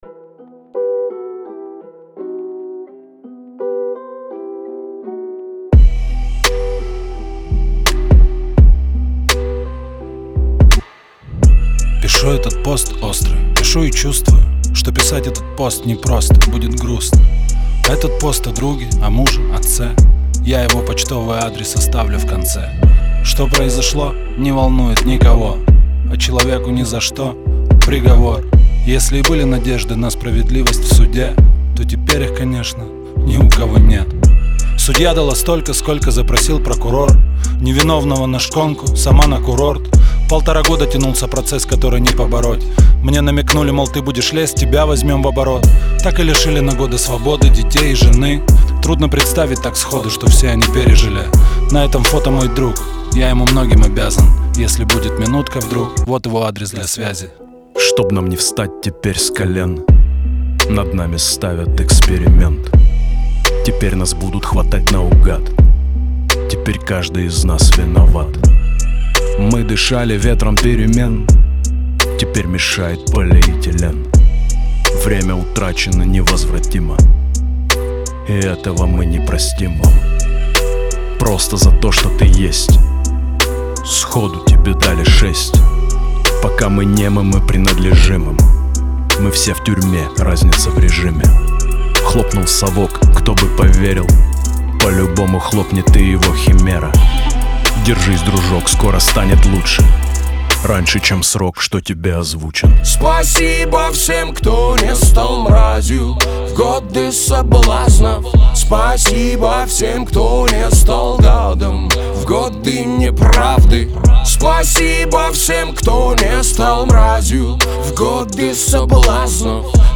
выполненная в жанре хип-хоп.